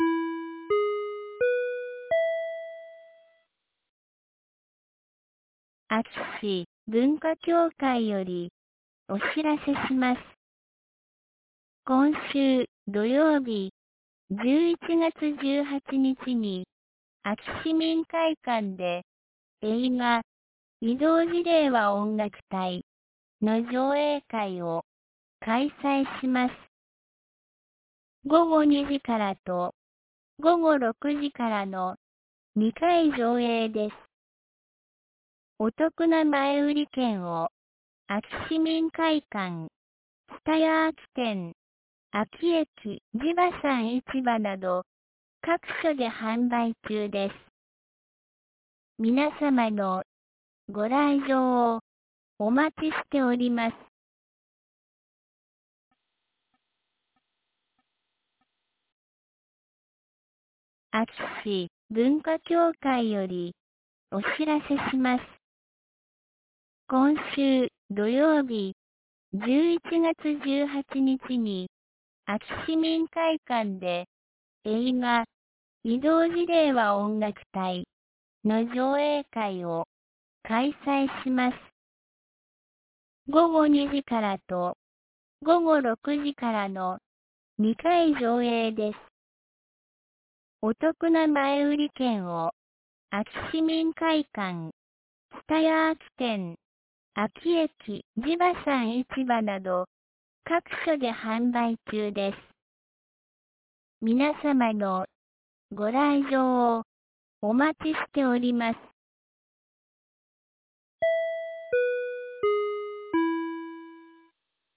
2023年11月15日 17時11分に、安芸市より全地区へ放送がありました。